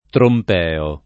[ tromp $ o ]